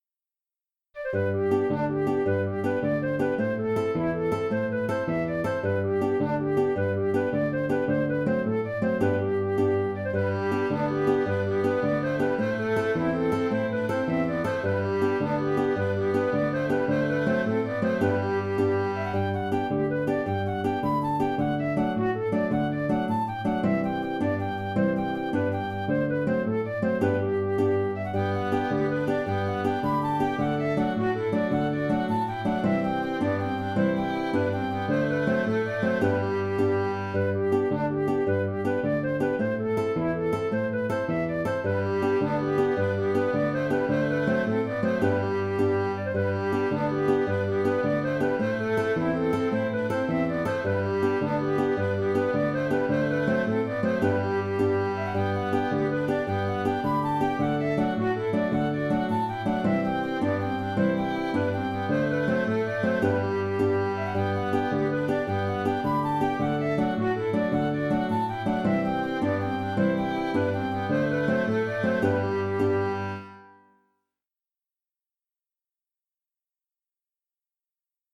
C’est une jig traditionnelle bien connue dont il existe plusieurs versions.
Il est préférable de jouer cette jig sur un tempo un peu plus rapide que sur le fichier audio.